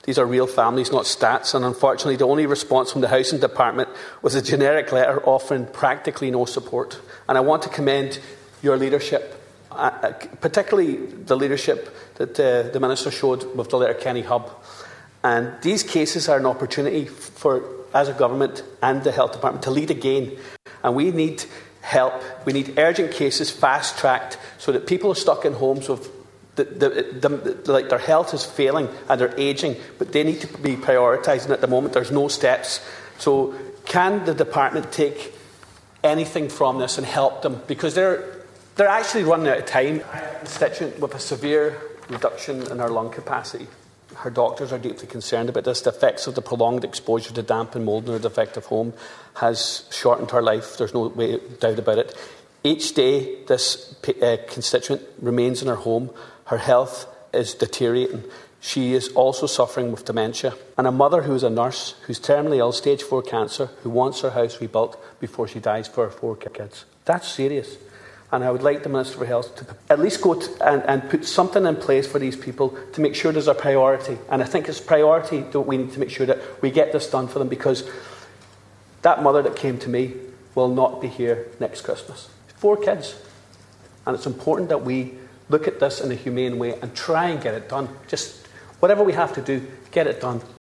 A Donegal TD has challenged the Government in the Dáil over the plight of seriously ill and terminally ill residents trapped in defective concrete homes.